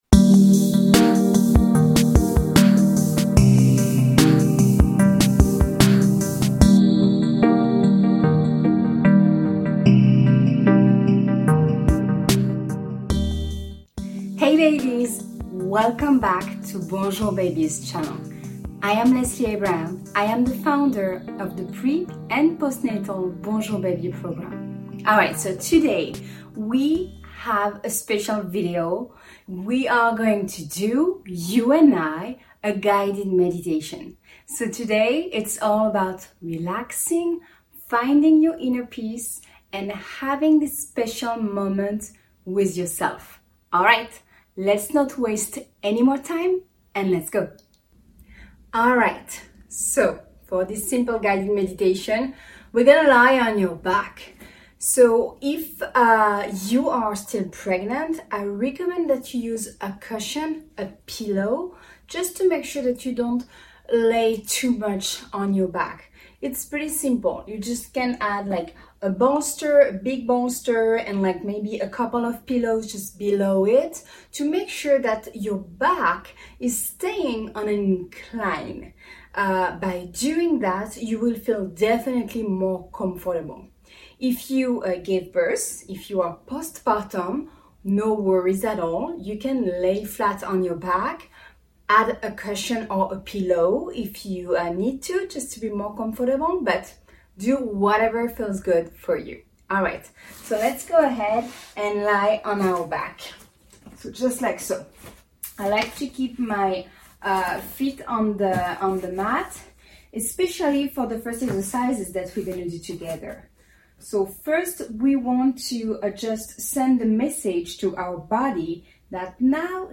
Try this guided relaxation session and meditation for pregnancy and beyond. Relax, breathe, stretch and meditate.
RELAXATION-AND-MEDITATION-SESSION-AUDIO-ON.mp3